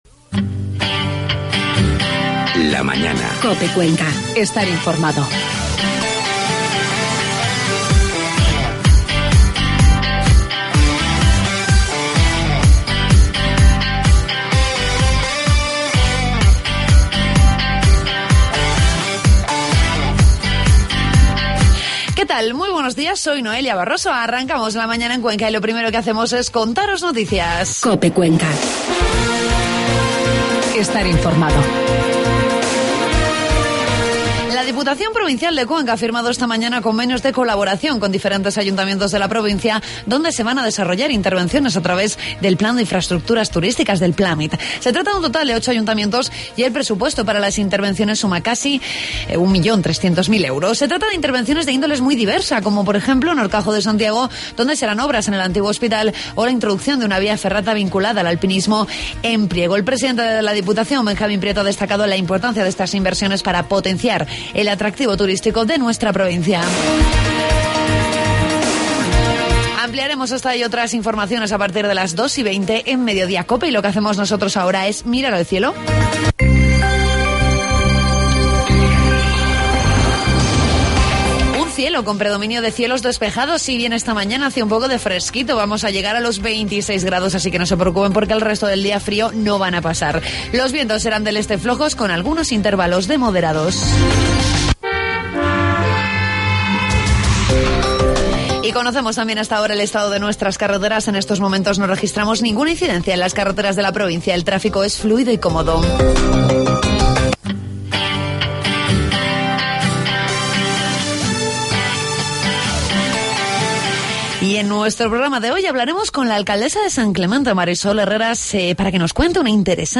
Entrevistamos a la alcaldesa de San Clemente, Mari Sol Herrera, con la que conocemos un interesante iniciativa contra el abandono y maltrato animal.